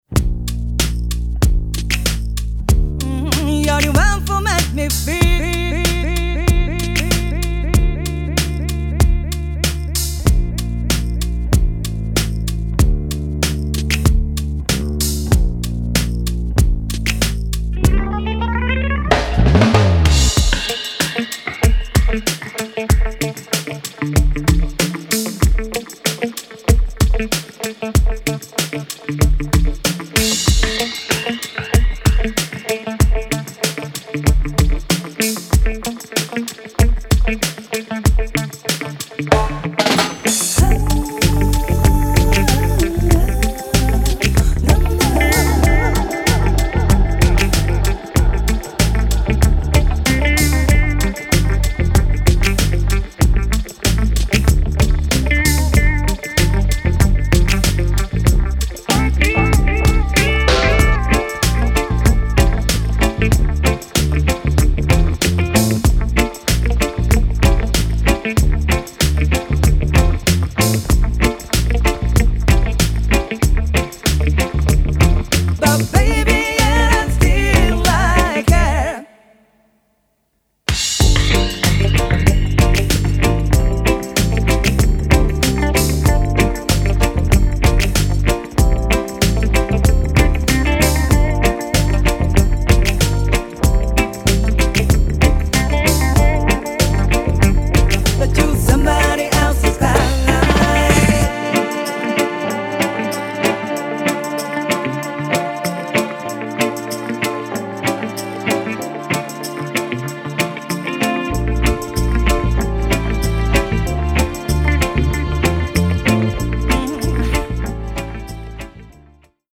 ジャンル(スタイル) REGGAE / LOVERS ROCK / SOUL